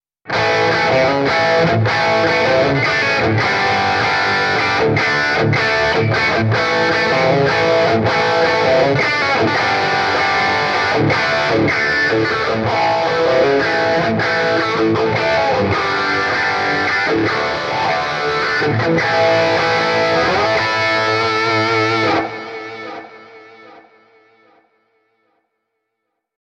This Amp Clone rig pack is made from a Mesa Boogie JP2C, Mark IV, Mark V, Mark VII and a Mesa Triaxis preamp head.
RAW AUDIO CLIPS ONLY, NO POST-PROCESSING EFFECTS